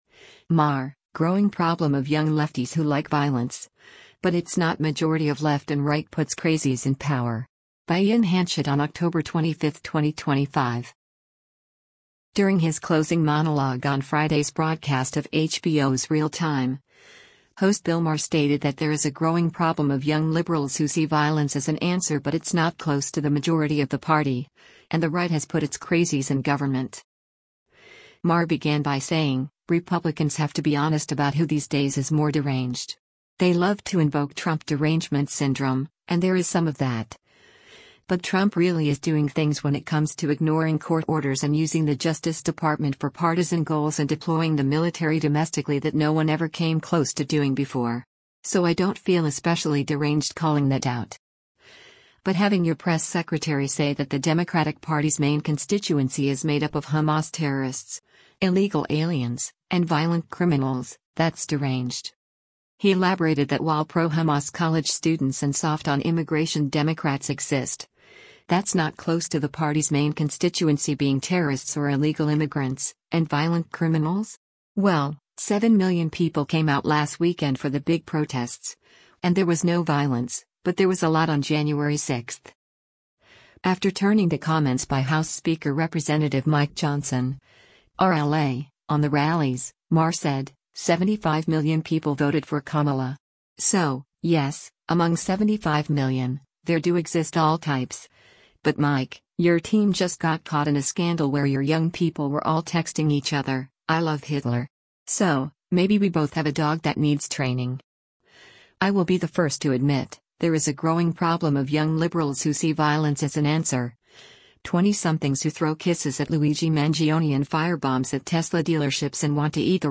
During his closing monologue on Friday’s broadcast of HBO’s “Real Time,” host Bill Maher stated that “there is a growing problem of young liberals who see violence as an answer” but it’s not close to the majority of the party, and the right has put its crazies in government.